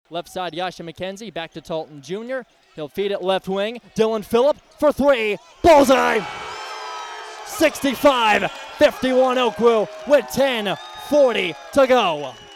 Coverage of the title game can be heard on KWON - AM 1400, FM 93.3 and 95.1. Pregame coverage will start at 6:45.